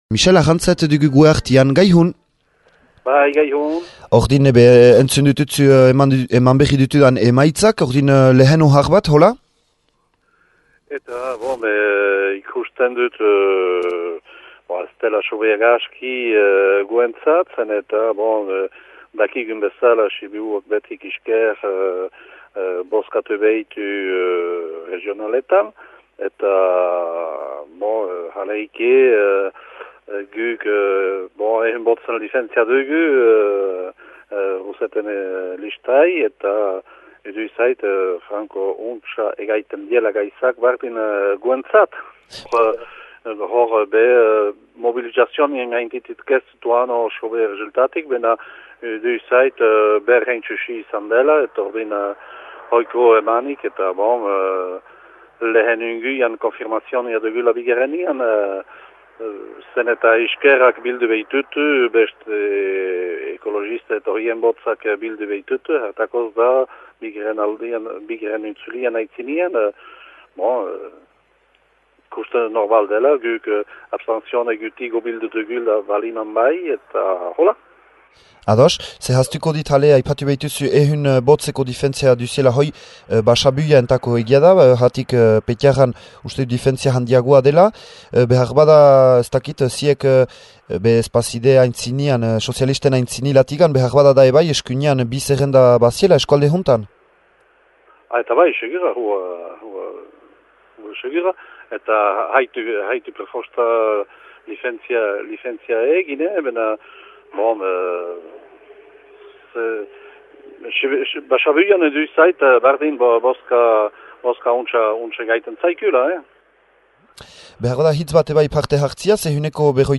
Entzün antenan hiru zerrendetako ordezkarien ihardokitzeak :